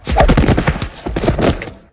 bodyslam.ogg